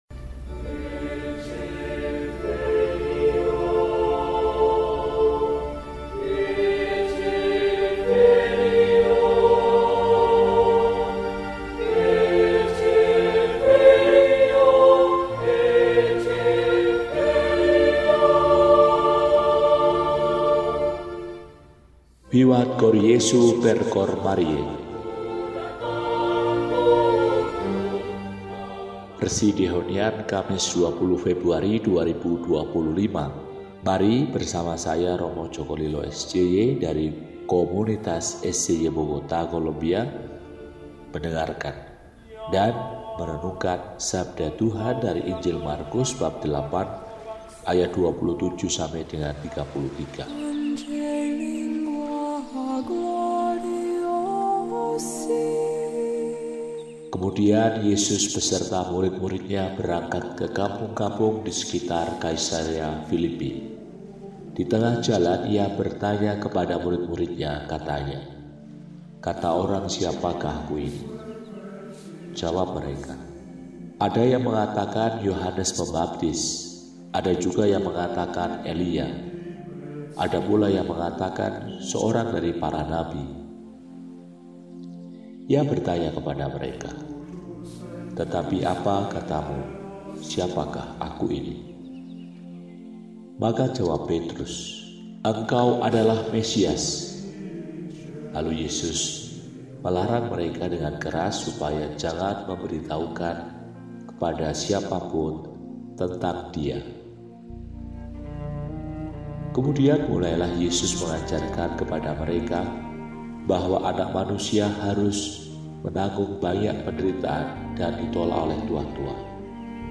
Kamis, 20 Februari 2025 – Hari Biasa Pekan VI – RESI (Renungan Singkat) DEHONIAN